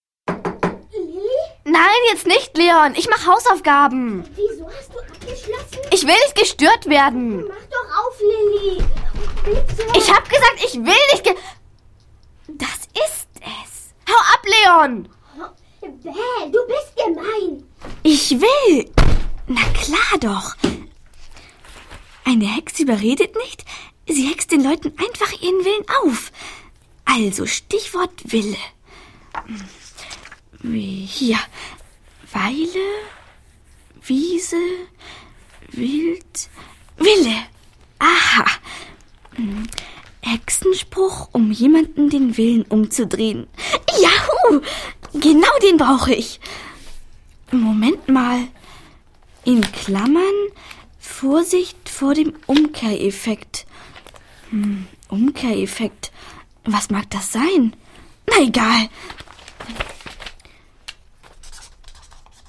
Ravensburger Hexe Lilli - Folge 6: und der Zirkuszauber ✔ tiptoi® Hörbuch ab 4 Jahren ✔ Jetzt online herunterladen!